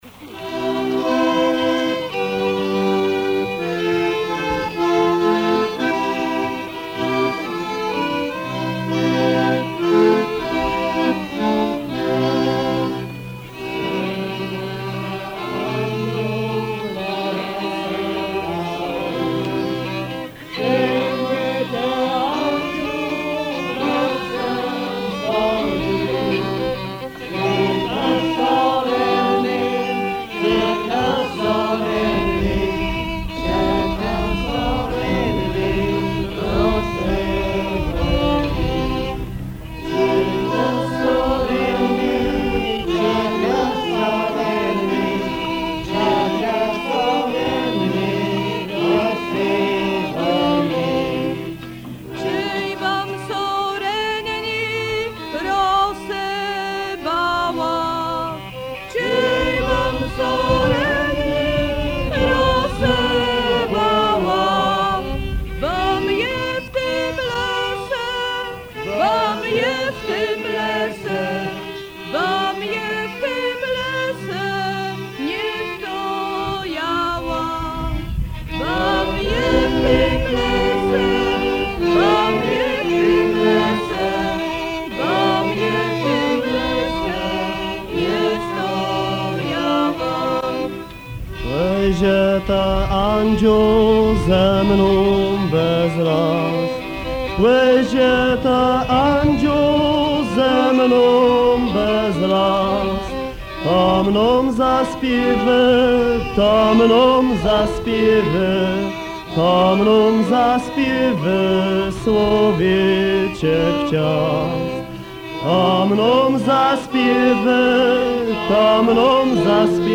Dix neuvième - Mélodie chantée
Pièce musicale éditée